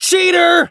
hotshot_hurt_06.wav